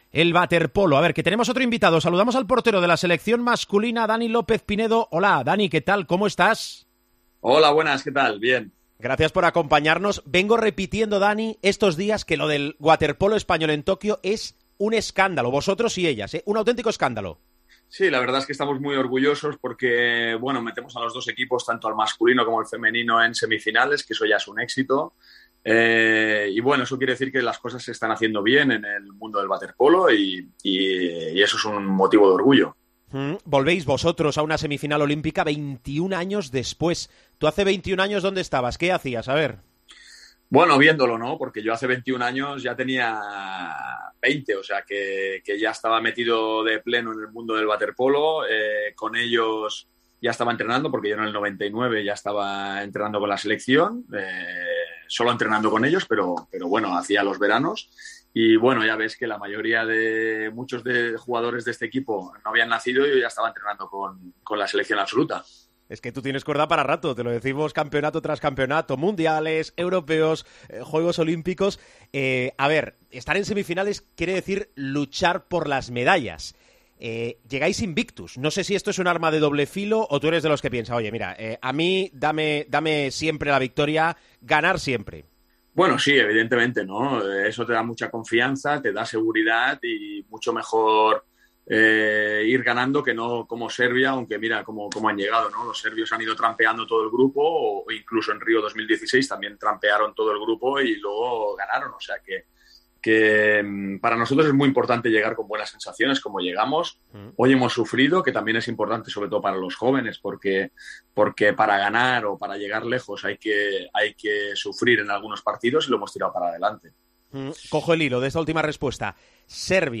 El guardameta de la selección española de waterpolo masculino, Daniel López Pinedo, atendió a El Partidazo de COPE después del pase a semifinales de los Juegos venciendo a USA.